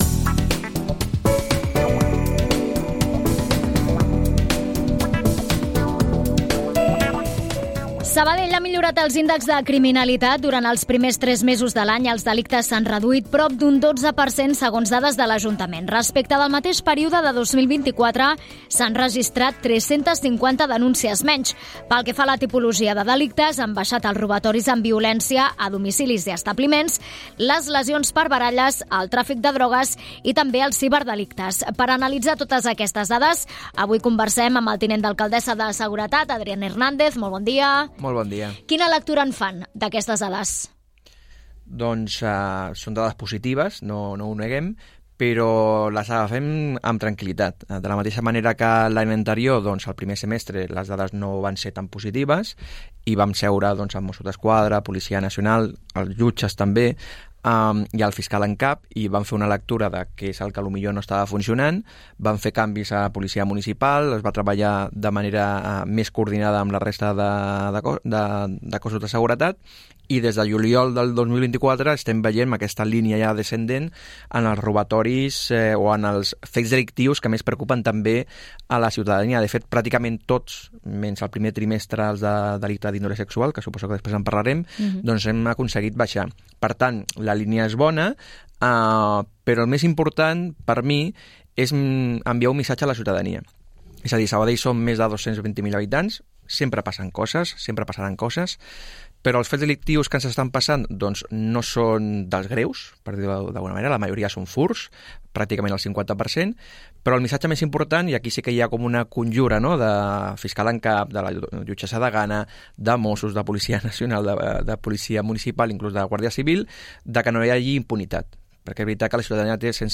Aquest és el missatge que ha volgut traslladar el tinent d'alcaldessa de Seguretat, Adrián Hernández, en una entrevista a RàdioSabadell, en què ha tornat a insistir que, per complicades que siguin algunes d'aquestes ocupacions, l'executiu local no tirarà la tovallola.